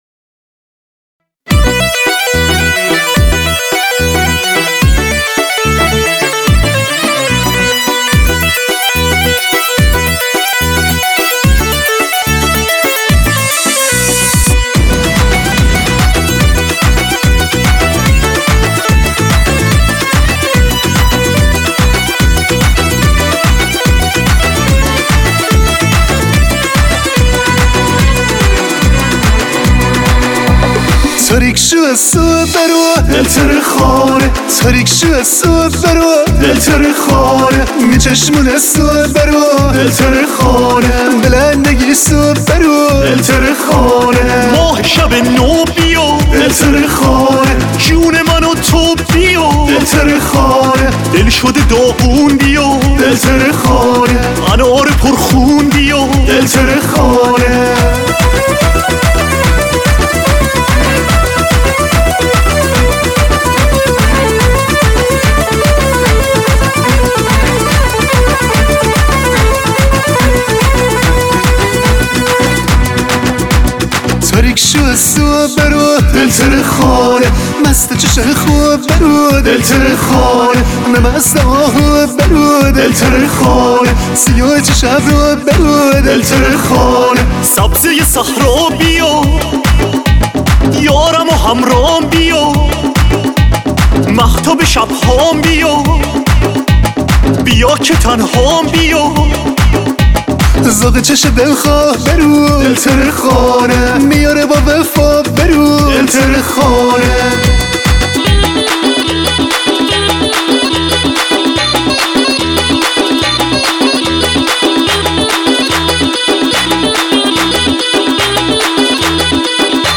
آهنگ شمالی شاد